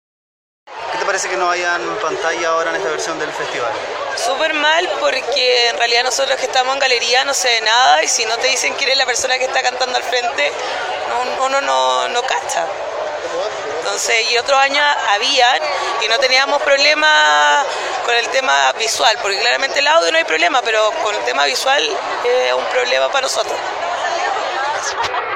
Una de las presentes también expresó a Página 7 su crítica sobre la situación.
CUNA-ASISTENTE-POR-PANTALLAS.mp3